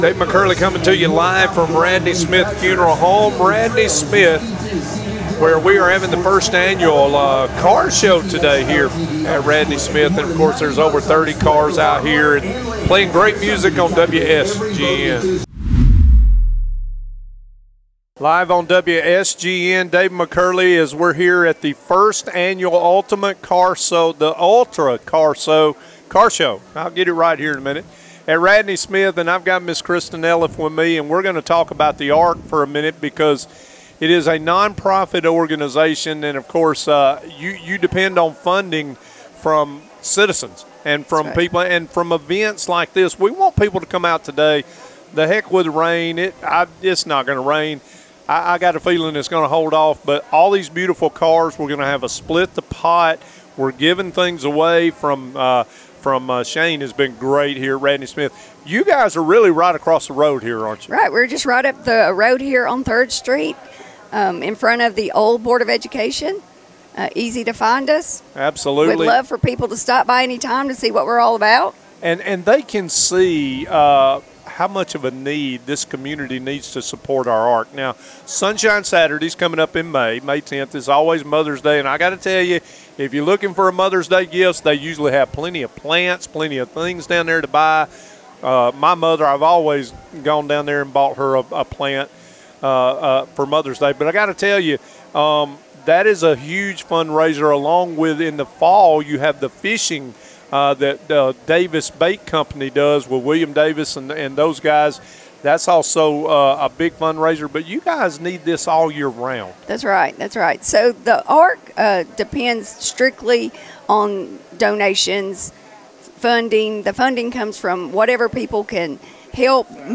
Live from the 1st Annual Ultimate Car Show presented by Radney-Smith Funeral Home